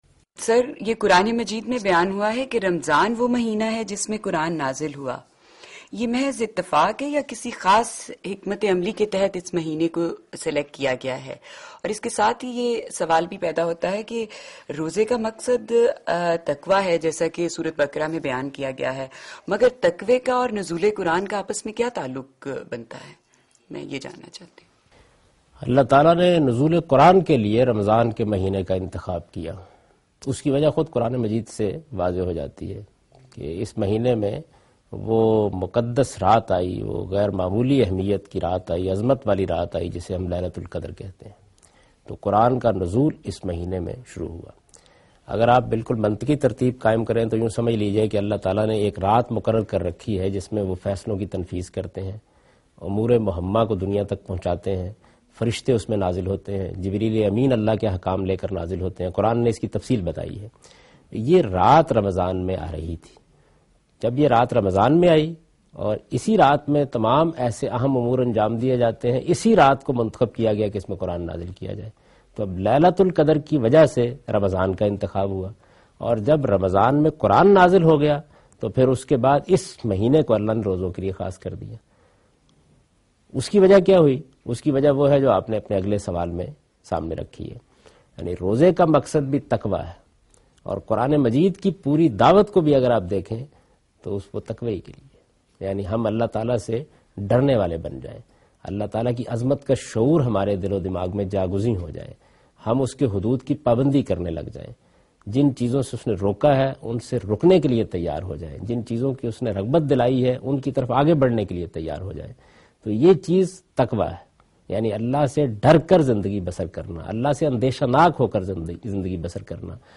Javed Ahmad Ghamidi answers a question regarding 73 Muslim Sects in program Deen o Danish on Dunya News.